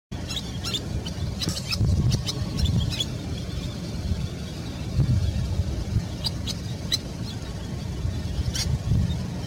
アジサシ 属   コ ア ジ サ シ １　　3-10b-04
鳴 き 声：キリッ、キリッと鳴く。
鳴き声１